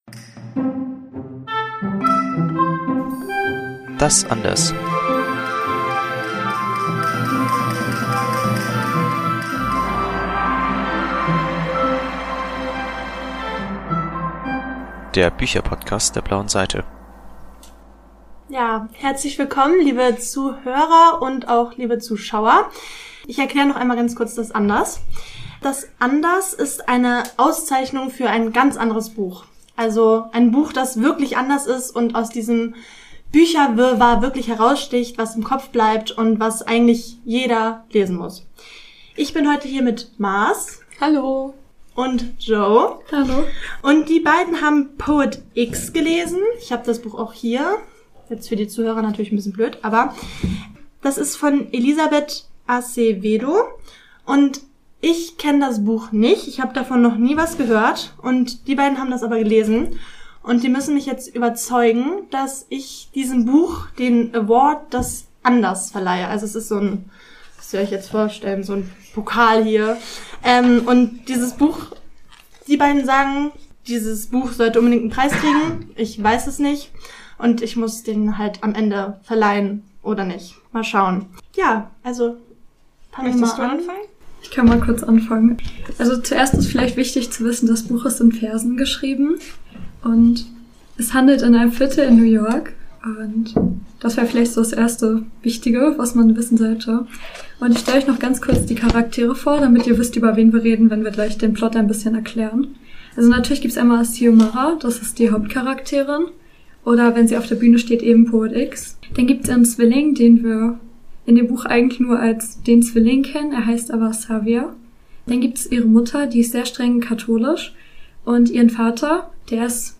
Live-Aufnahme vom Podcast-Marathon "Podcast und Plätzchen" im Dezember 2024 Mehr